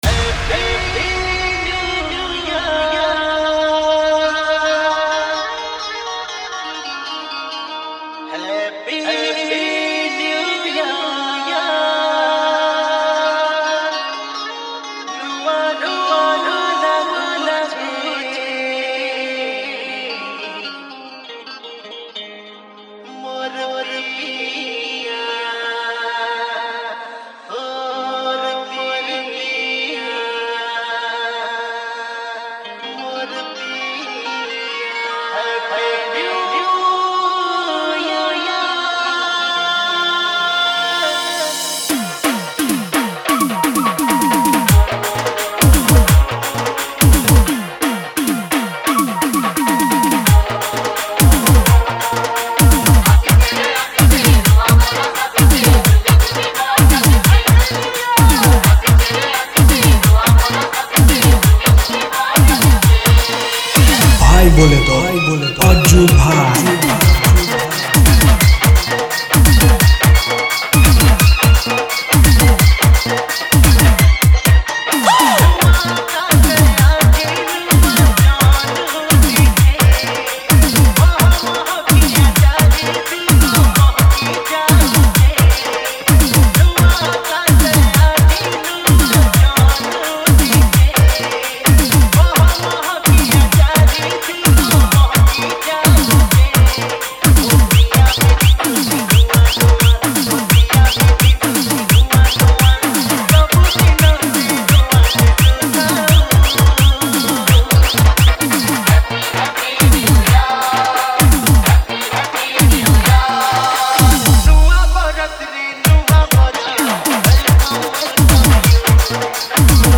New Year Special Dj Remix Songs Download